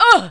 ALLVOICES